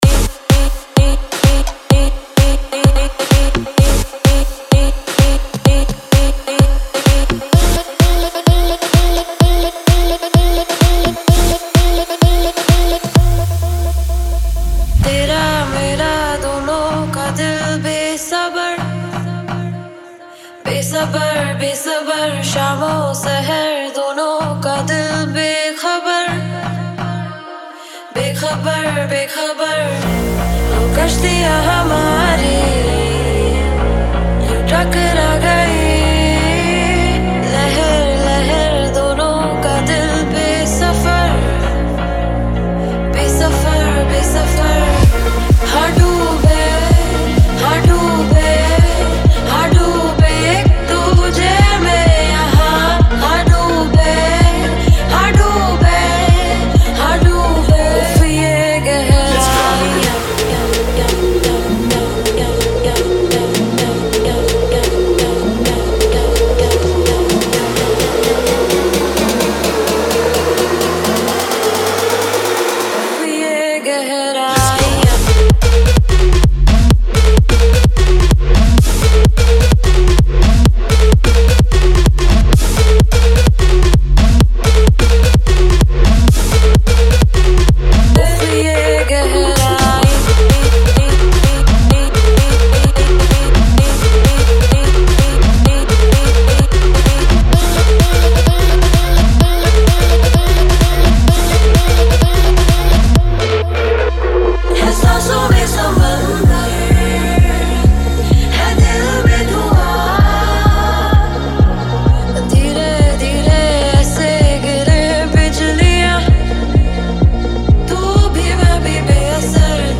EDM Remix | Dance Remix Song
Category: Latest Dj Remix Song